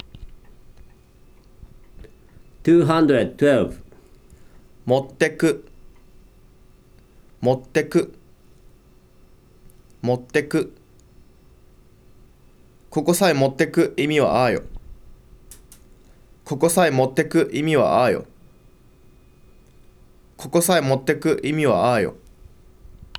If you click the word in a celll in the table, then you can hear the `non-past' form of the verb and a sentence containing the `non-past' form as the verb of the adnominal clause in Saga western dialect.